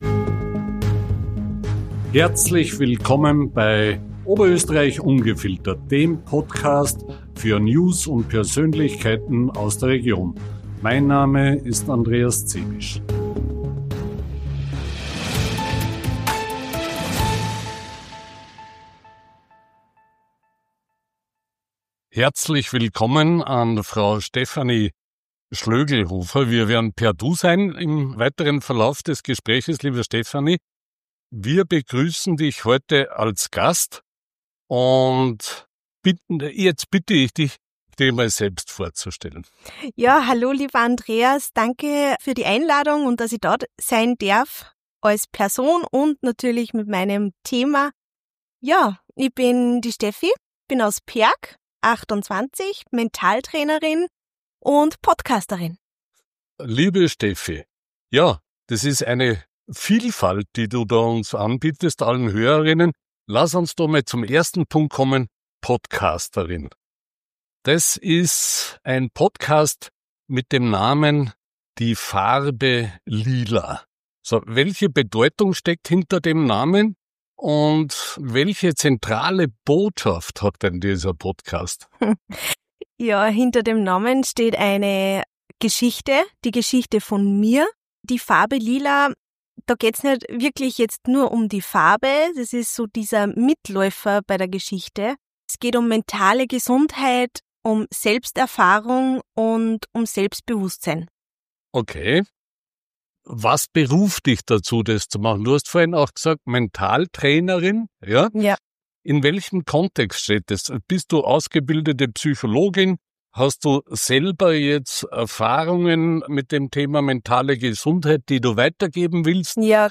Dieses Interview ist kein Blick zurück – sondern ein Sichtbarwerden.